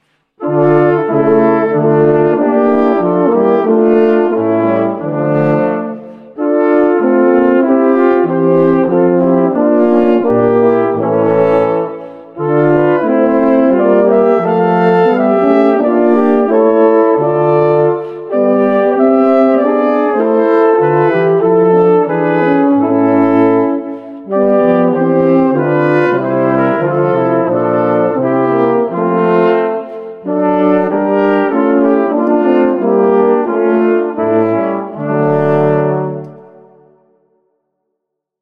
Barocke Kirchenmusik für Blechblasinstrumente
2x Flügelhorn, F-B-Horn, Es-Tuba [0:38]